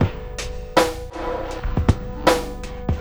Index of /90_sSampleCDs/Spectrasonic Distorted Reality 2/Partition A/03 80-89 BPM